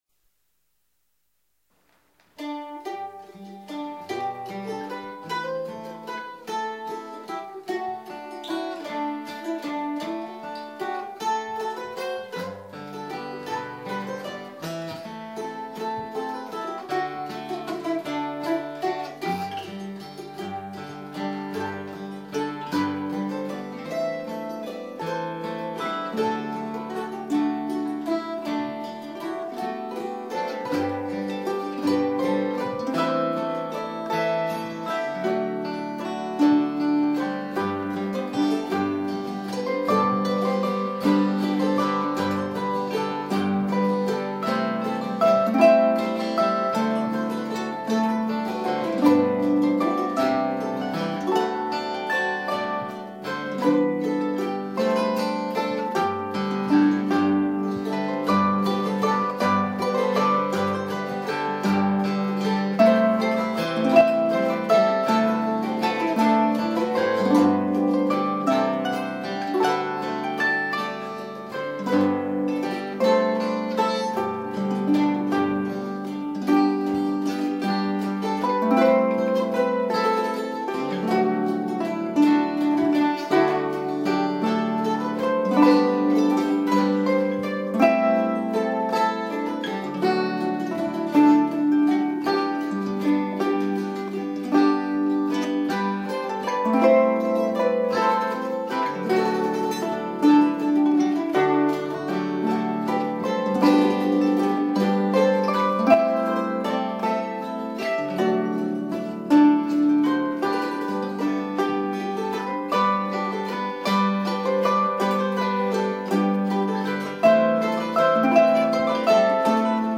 Recorded at Flying Fiddle Studio
Fiddle
Guitar